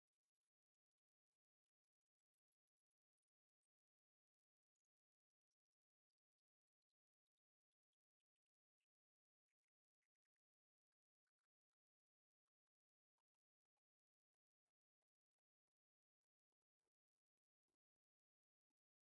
Je me suis mis en quête d'un son testeur et après quelques longues minutes de recherche, j'ai trouvé ce logiciel qui m'a permi de bidouiller un son test, écoutez...
Sample Rate 44,.k - 16-bit, stéréo • 20.00 > 20 000 Hz • wave type: Square • +12 Dbs • phase shift +180.00